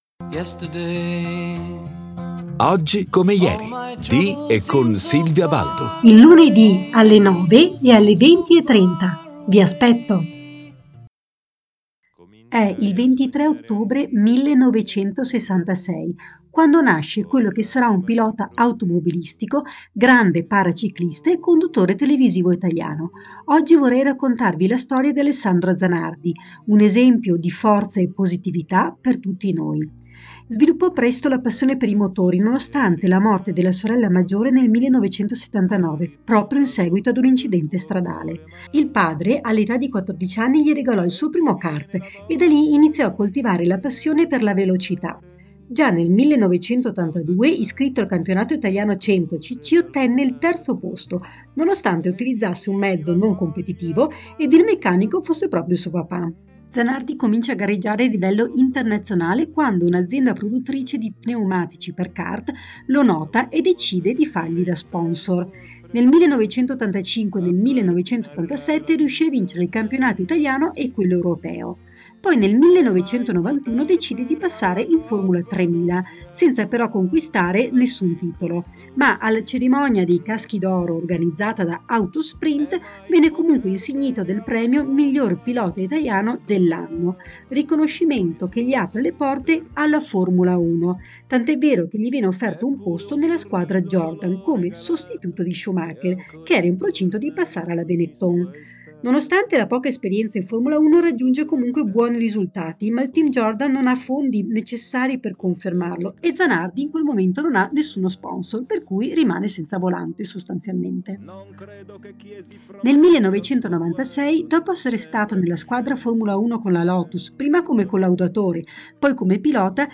GIORNALE RADIOPIU 24 OTTOBRE 2023